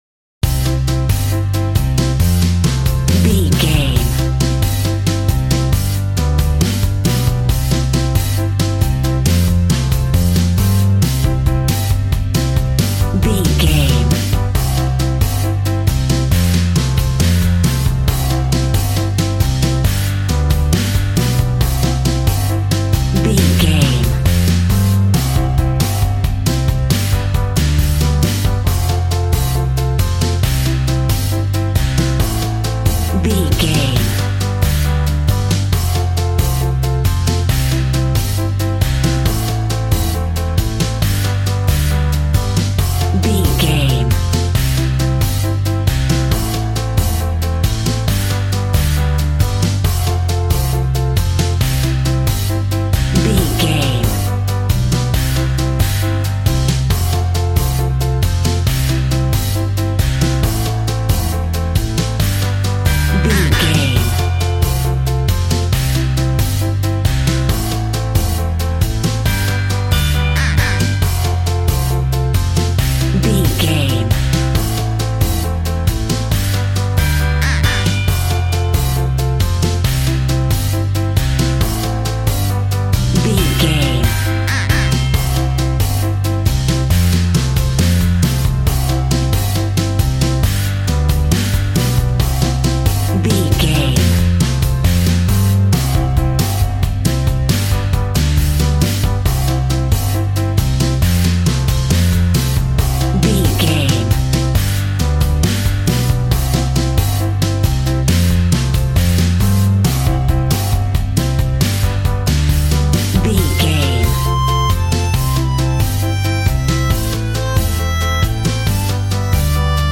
Ionian/Major
cheerful/happy
bouncy
electric piano
electric guitar
drum machine